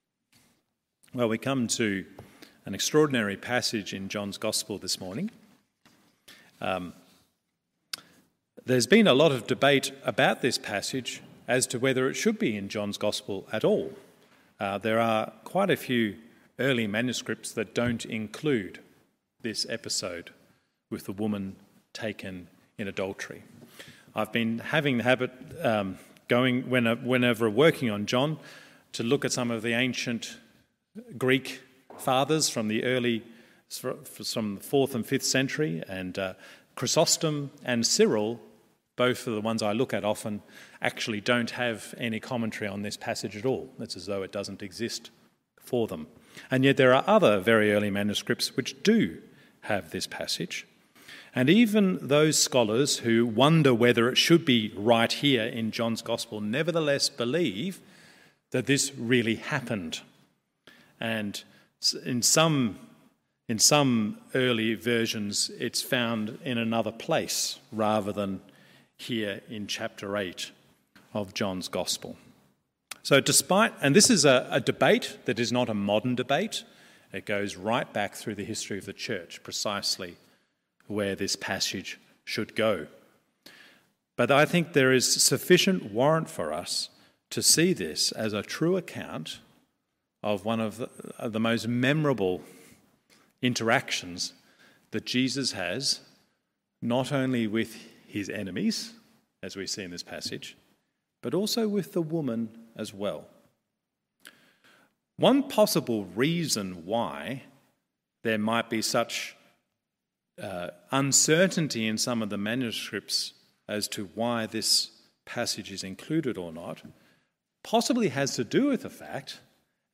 MORNING SERVICE John 8:1-11…